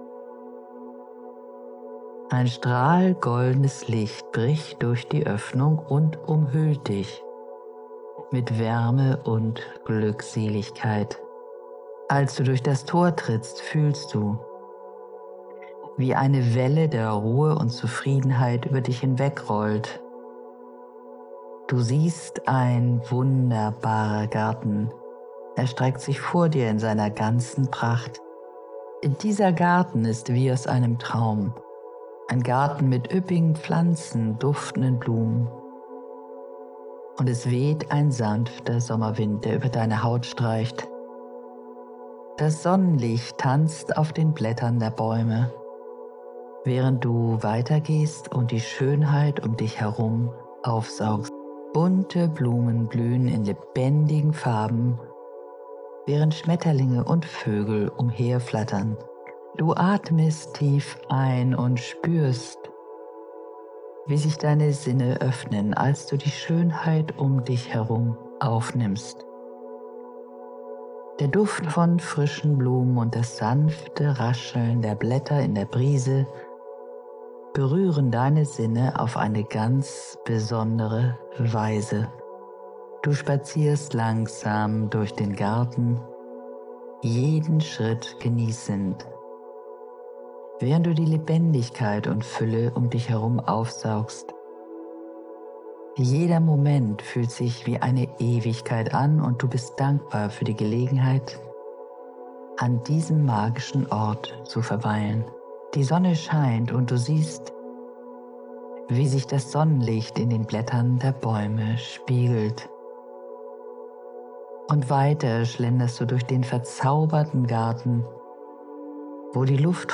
Diese Meditation ist eine Fantasiereise in einen Garten, in dem die gesamte Natur raucht.
• Musik: Ja;